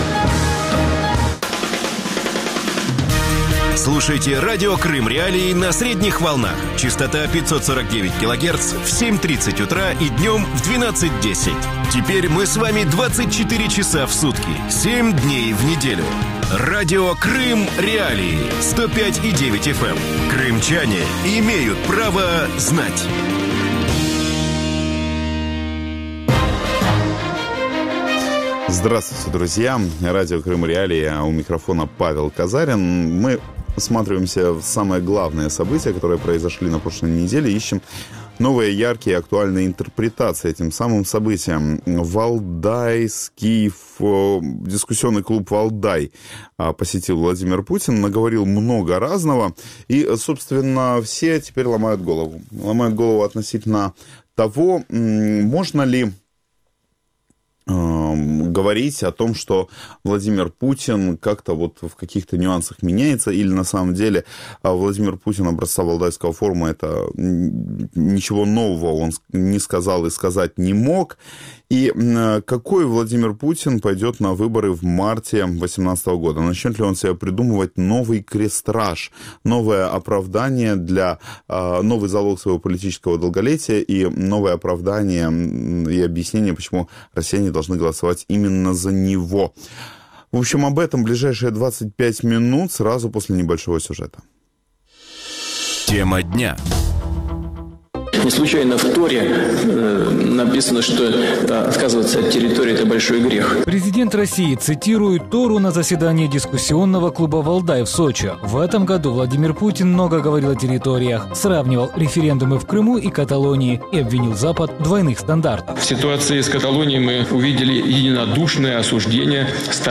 Что значат новые заявления президента России Владимира Путина о Крыме озвученные на форуме? Какую политическую картину формируют участники этого форума? Гости эфира